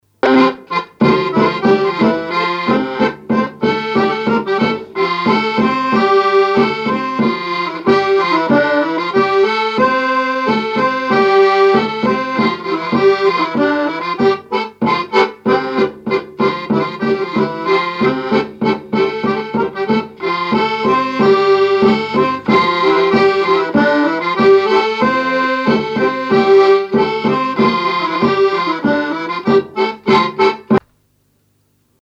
Chants brefs - A danser
danse : scottich sept pas
répertoire à l'accordéon chromatique et grosse caisse
Pièce musicale inédite